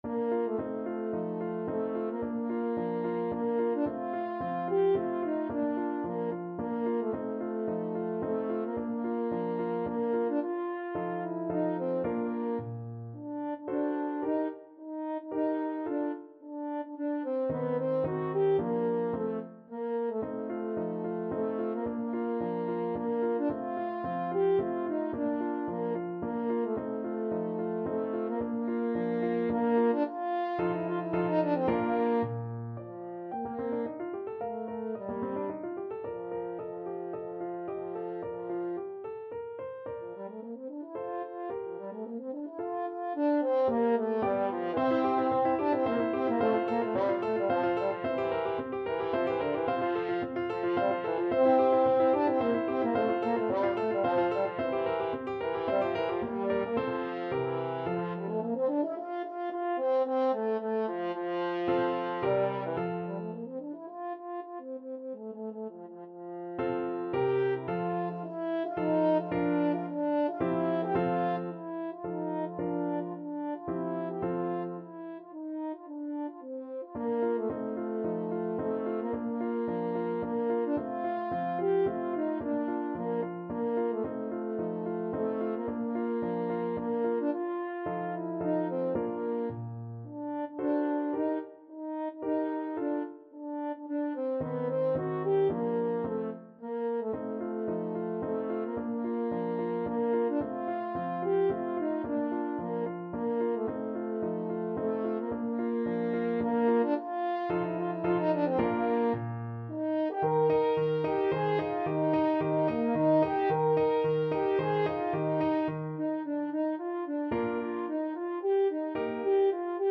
French Horn
Bb major (Sounding Pitch) F major (French Horn in F) (View more Bb major Music for French Horn )
3/4 (View more 3/4 Music)
II: Tempo di Menuetto =110
Classical (View more Classical French Horn Music)